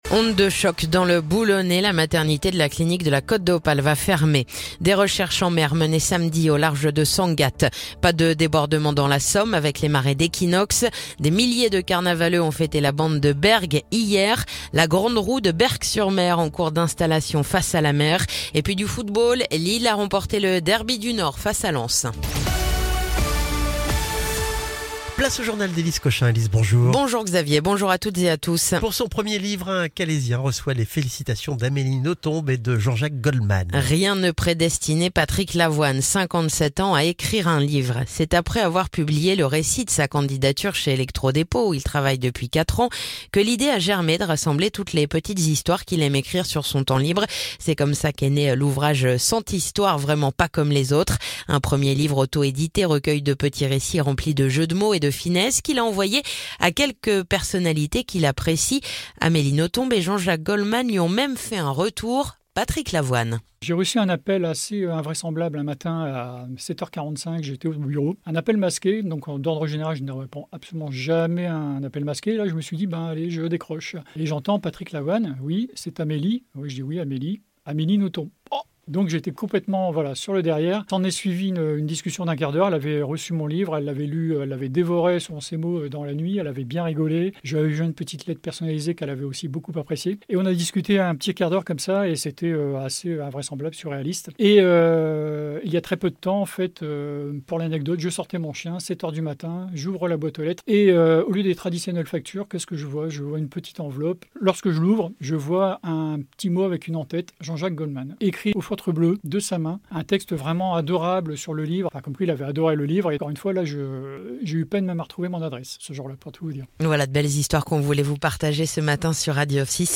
Le journal du lundi 31 mars